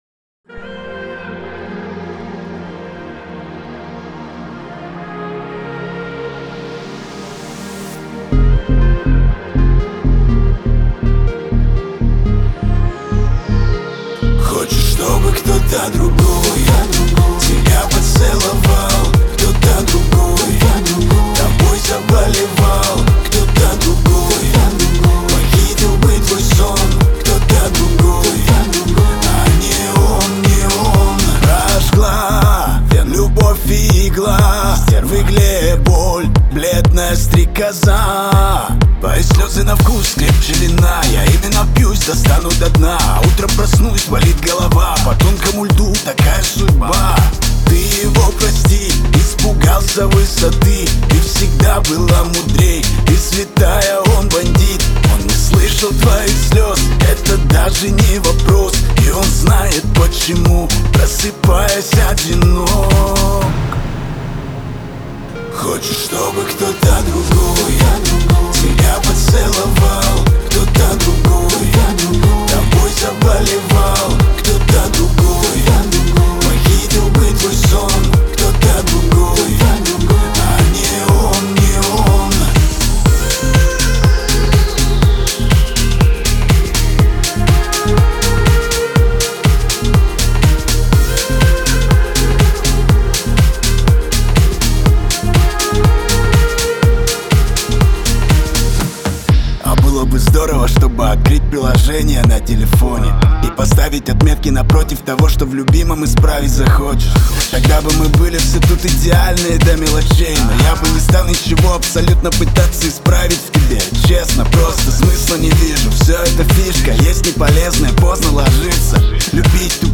это проникновенный хип-хоп трек с меланхоличным настроением.
сочетая рэп с мелодичными элементами.